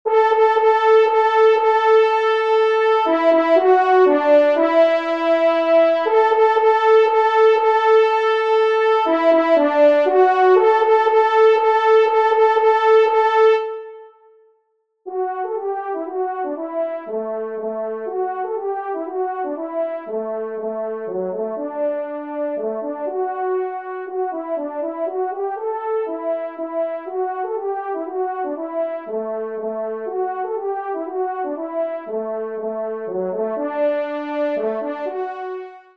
Genre : Divertissement pour Trompes ou Cors
Pupitre 1° Cor